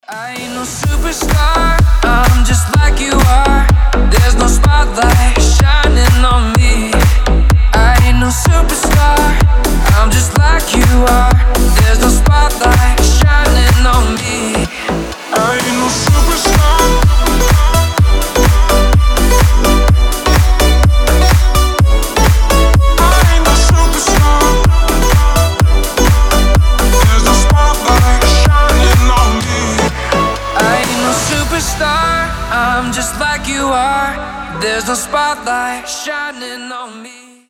• Качество: 320, Stereo
мужской голос
громкие
deep house
мелодичные
Club House
ремиксы